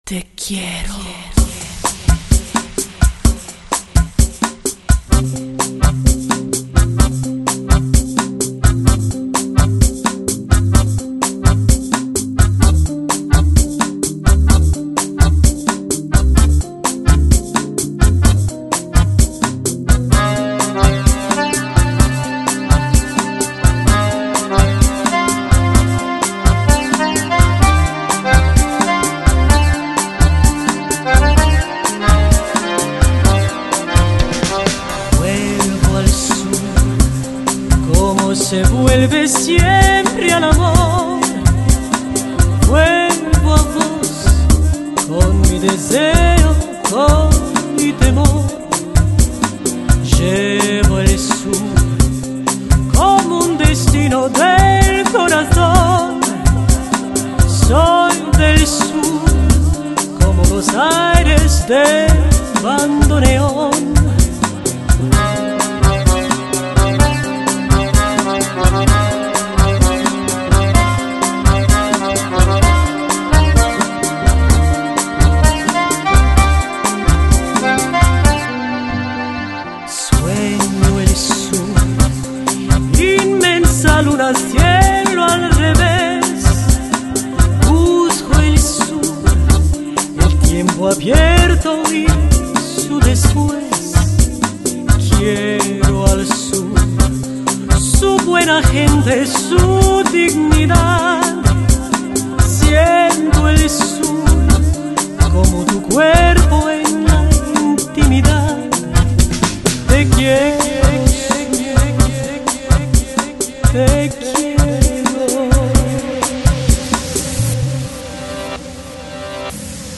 Lounge, Chillout Год издания